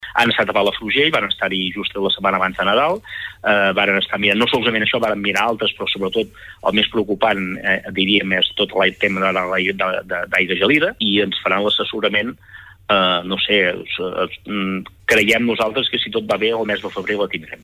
PalafrugellEntrevistes Supermatí
L’alcalde de Palafrugell, Josep Piferrer, ha explicat en una entrevista al Supermatí que esperen tenir resposta del govern al febrer, conjuntament amb una guia que els indiqui si hi ha més projectes al municipi que es poden aturar.